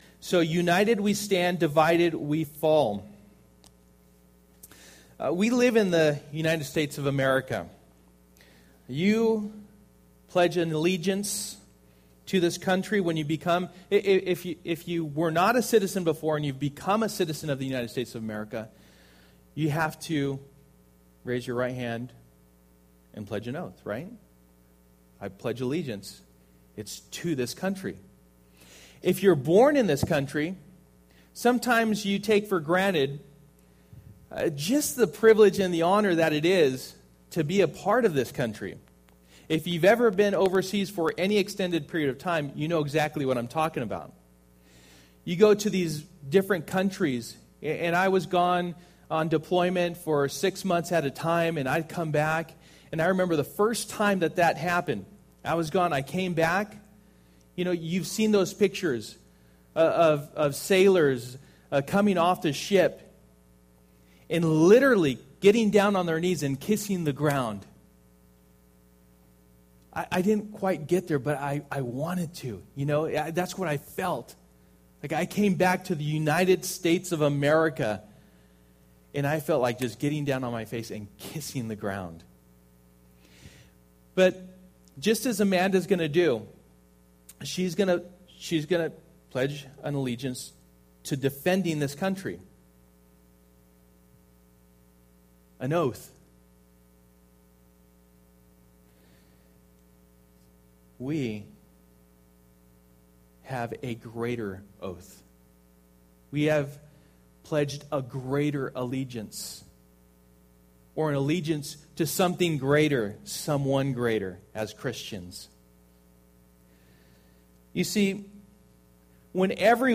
Passage: Ephesians 4:1-32 Service: Sunday Morning